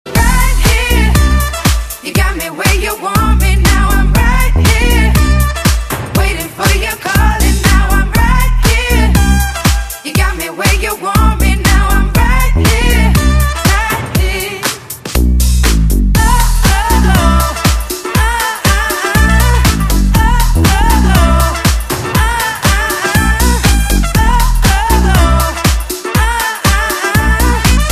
M4R铃声, MP3铃声, 欧美歌曲 121 首发日期：2018-05-15 06:37 星期二